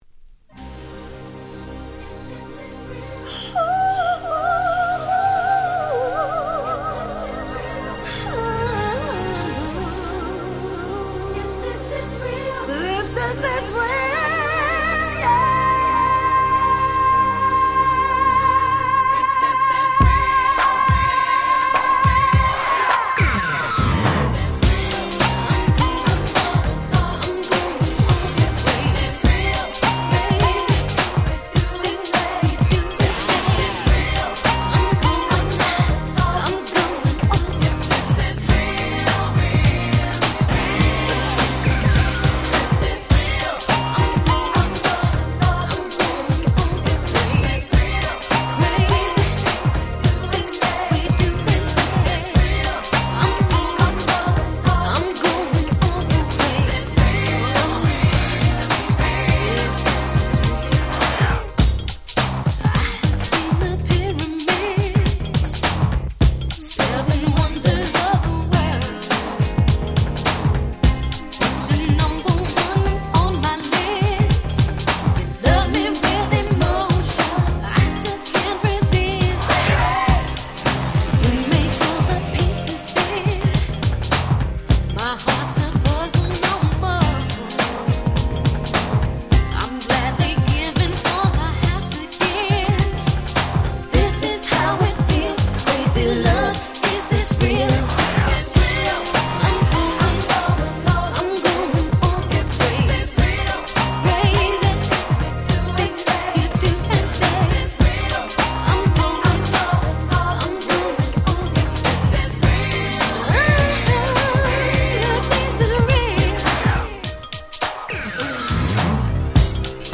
94' Very Nice R&B !!